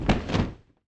Wing Flap Big1.wav